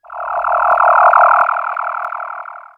Machine07.wav